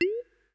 window-maximized.ogg